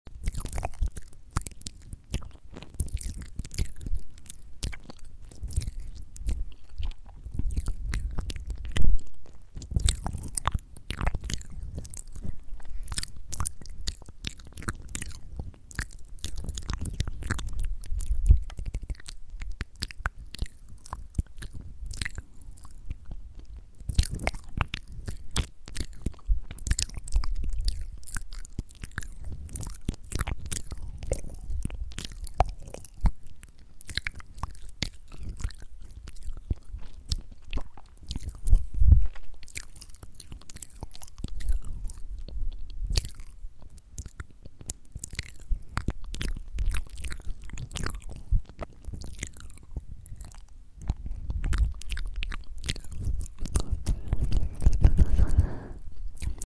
Roblox ASMR Mouth Sounds sound effects free download
Roblox ASMR - Mouth Sounds While Collecting Hearts